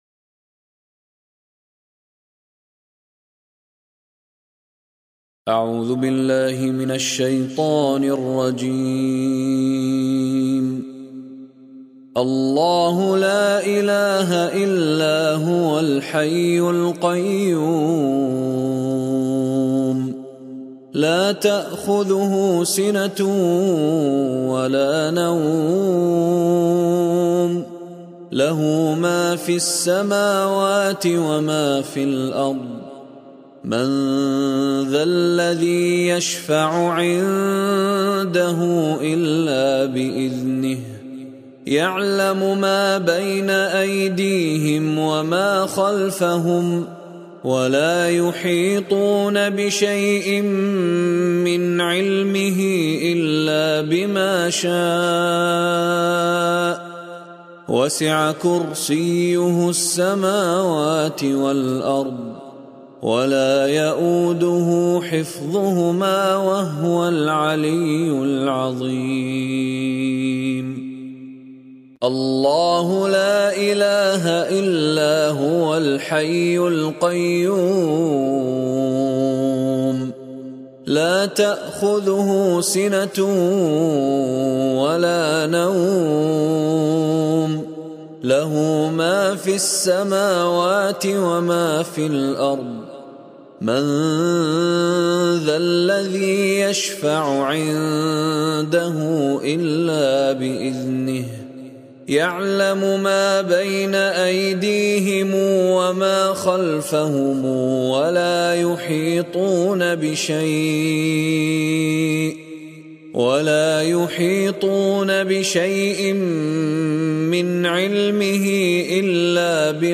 Ayat Al-Kursi 10 Different Qiraat By Qari Mishary mp3
Ayat Al-Kursi 10 Different Qiraat By Qari Mishary Al-Rashid Al Afasy.mp3